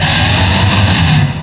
pokeemerald / sound / direct_sound_samples / cries / druddigon.aif